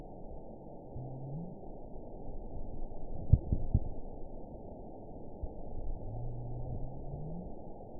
event 916288 date 12/29/22 time 09:18:05 GMT (2 years, 4 months ago) score 8.90 location TSS-AB05 detected by nrw target species NRW annotations +NRW Spectrogram: Frequency (kHz) vs. Time (s) audio not available .wav